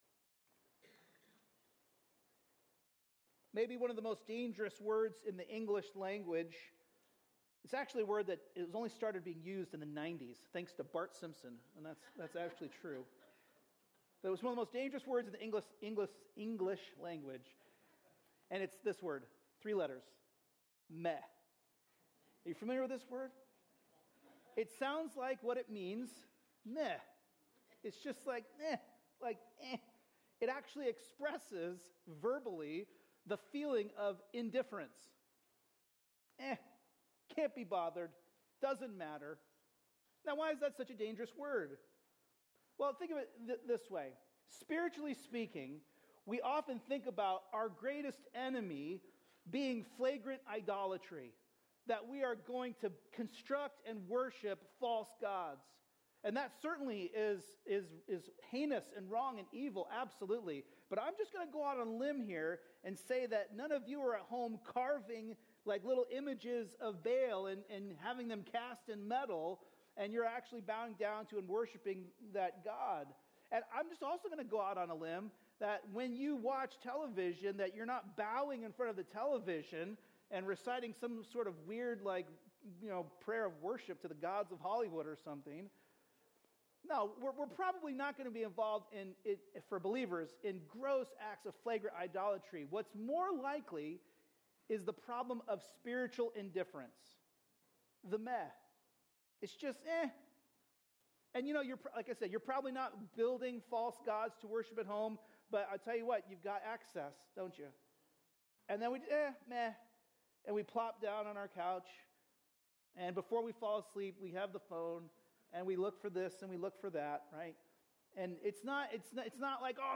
A message from the series "Ezra/Nehemiah." In Ezra 9:1-15, we learn that separation from the world is not optional, it is essential.